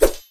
Sword_03.ogg